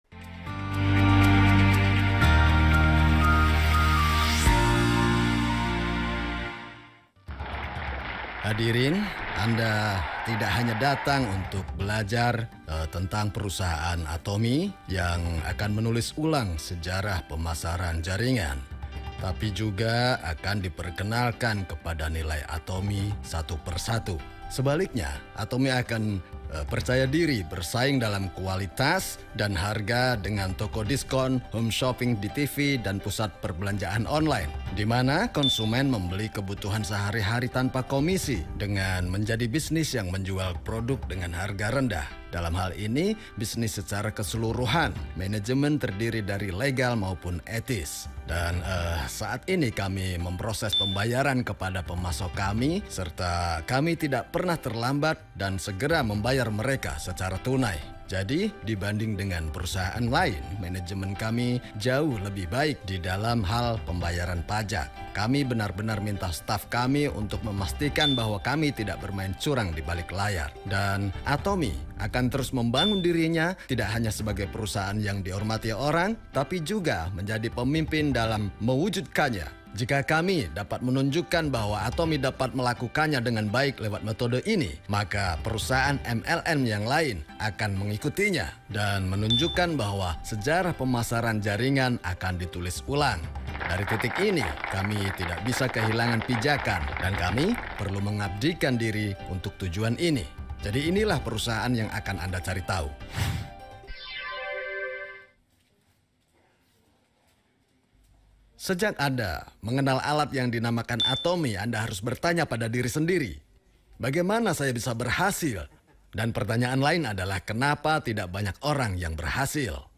Chairman Lecture - Kehidupan Seimbang & Skenario Kehidupan